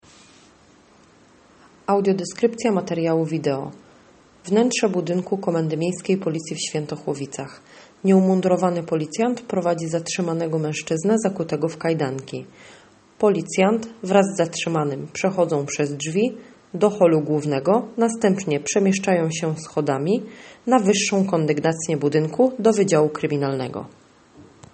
Nagranie audio audiodeskrypcja materiału wideo